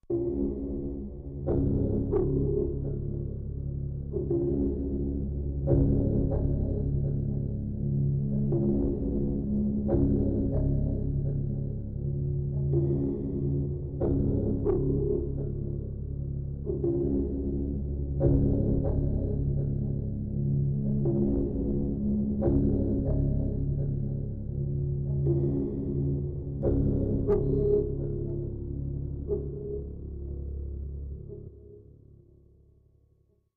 Foreclosure Sparse Low Rhythmic Pulses Echo